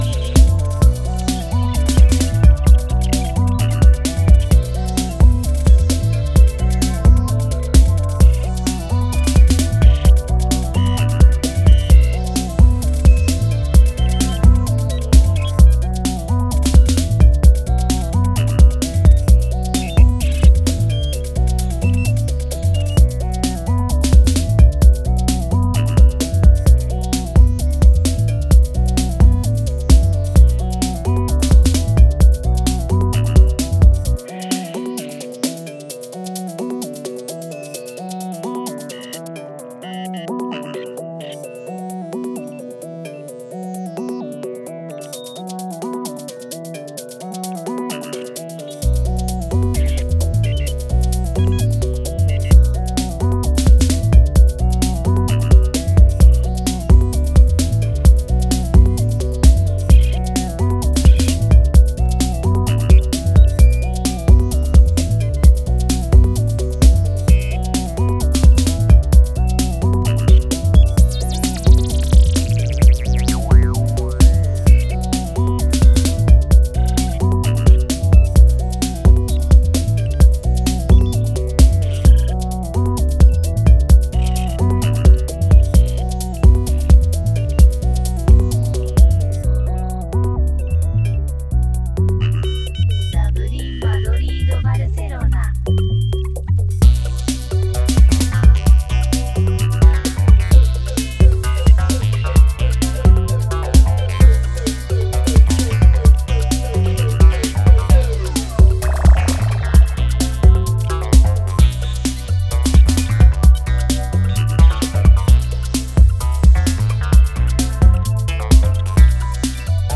broken take on minimal